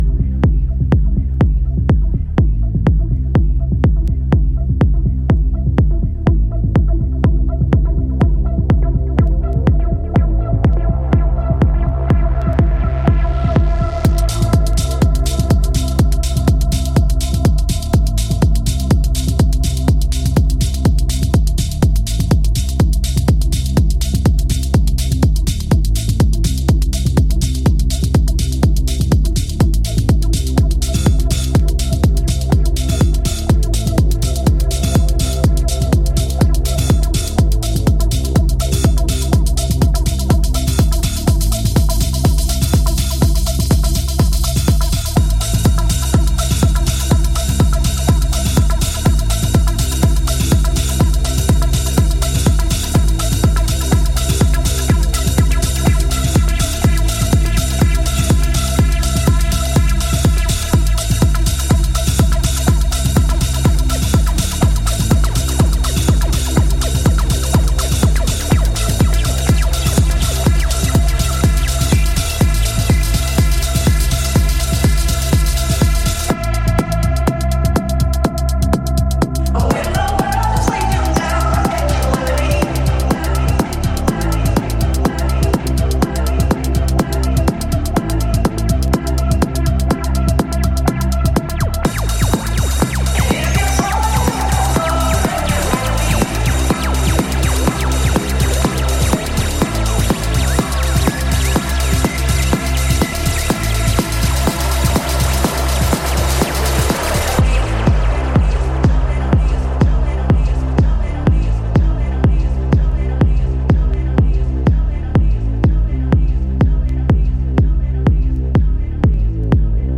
ジャンル(スタイル) HOUSE / DETROIT TECHNO